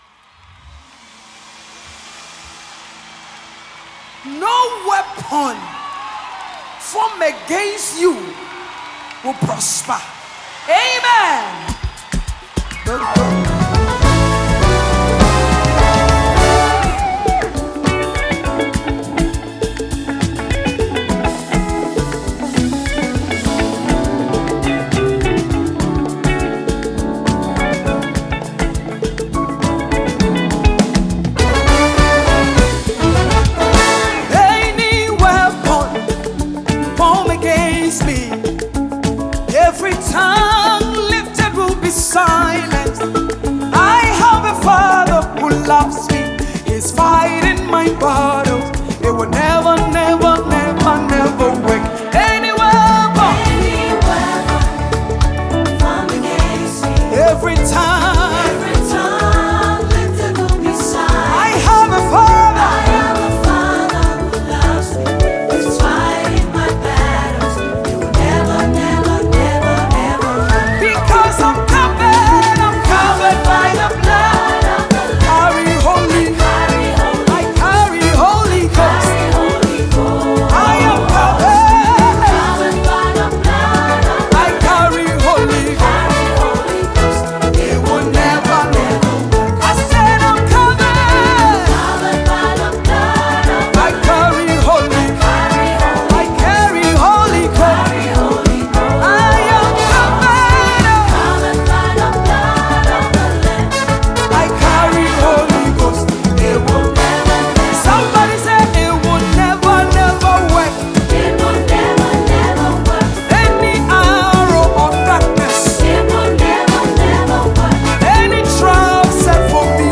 Enjoy the latest tune from Ghanaian Gospel Musician